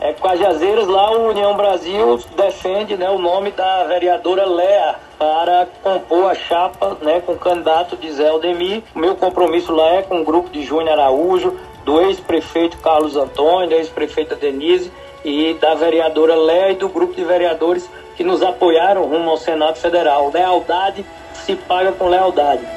Em entrevista ao programa Arapuan Verdade, da Rádio Arapuan FM, Efraim afirmou que o posicionamento do União é de apoiar o nome de Léa para a vice, retribuindo o apoio que teve do grupo na sua corrida para o Senado Federal nas eleições de 2022.